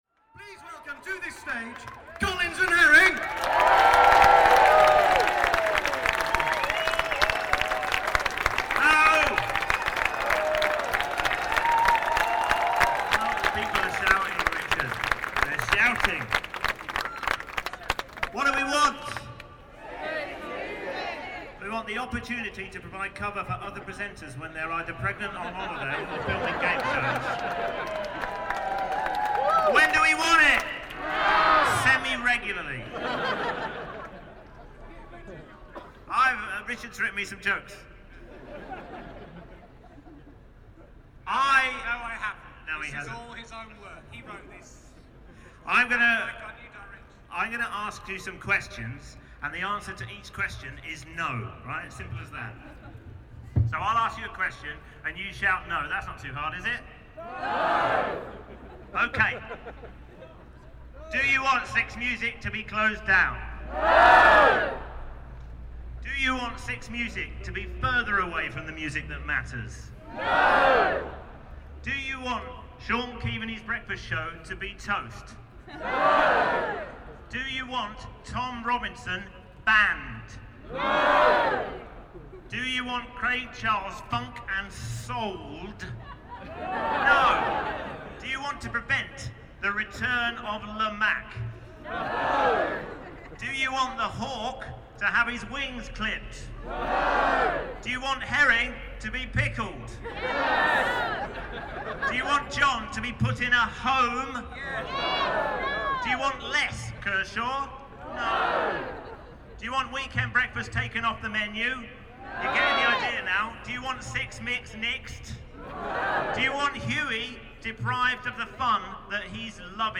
Tags: Rallies and demonstrations Rallies and demonstrations clips UK London Rallies and demonstrations sounds